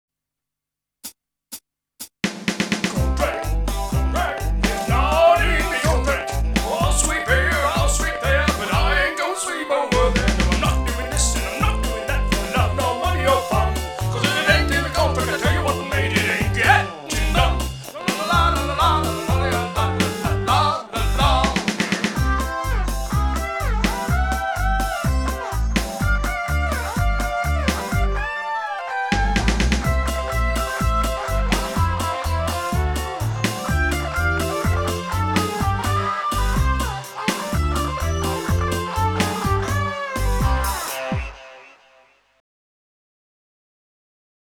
Around the end of the 90s I was asked to submit tracks to a high street retailer to be used as background music to "provide a calming atmosphere conducive to shopping".So I submitted some of the tracks above.